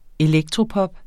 Udtale [ eˈlεgtʁoˌpʌb ]